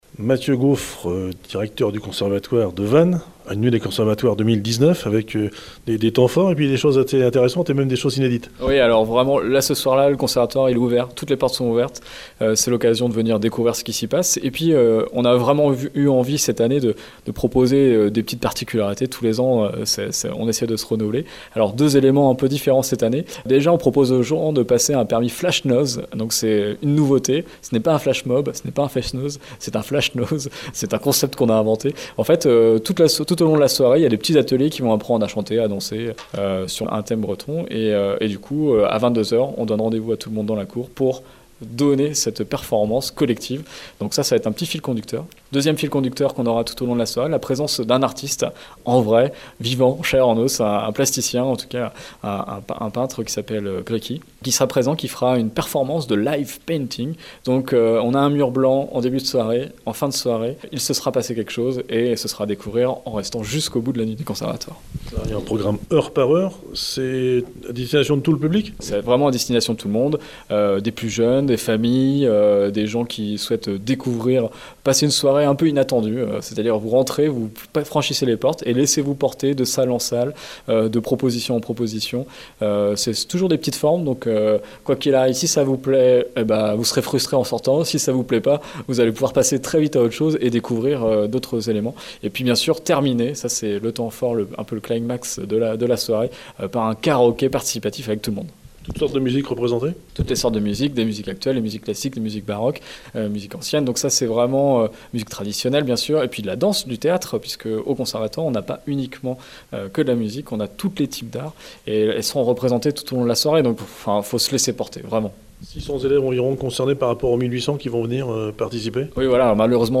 Présentation de cette nuit à Vannes et Interviews
Gabriel Sauvet – Maire adjoint à la Culture à la Ville de Vannes